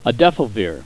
Pronunciation
(a DEF o veer)